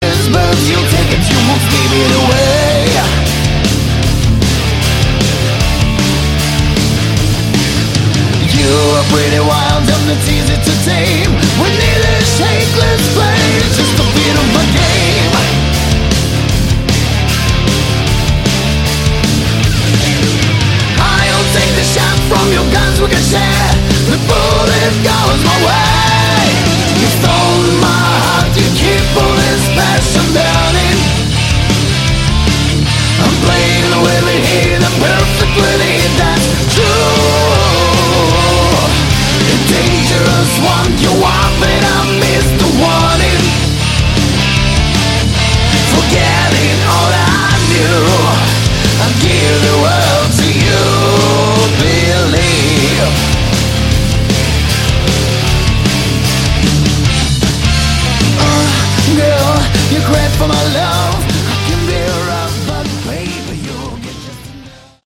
Category: Hard Rock
guitar, backing vocals
lead vocals
bass, backing vocals
drums